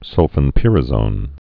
(sŭlfĭn-pĭrə-zōn)